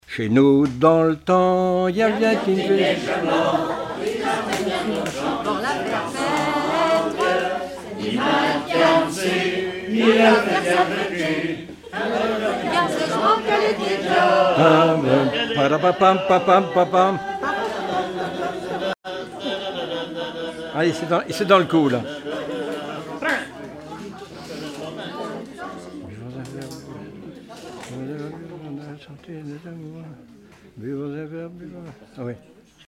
Répertoire de chansons populaires et traditionnelles
Pièce musicale inédite